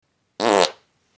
Funny Fart Sound Button: Unblocked Meme Soundboard
Funny Fart Sound Effects